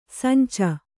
♪ sanca